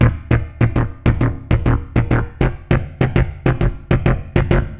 Dance music bass loop - 100bpm 56